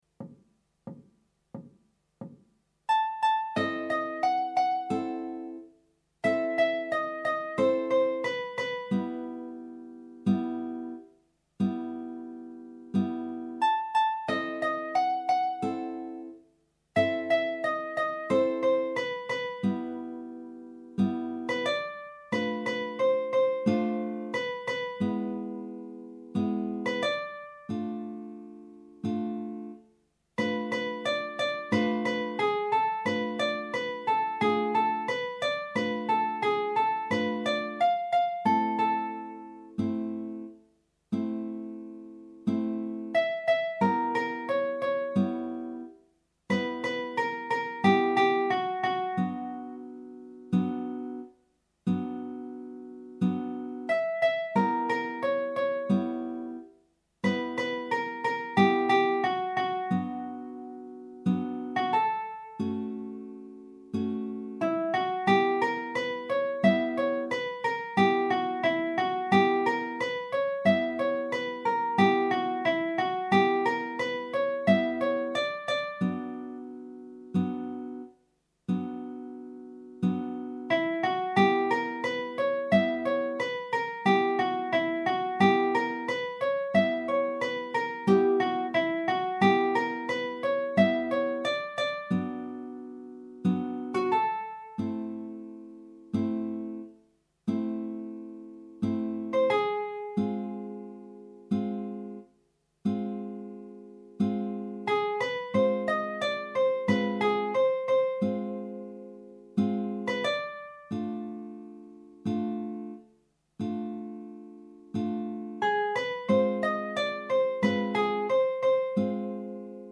minus Guitar 3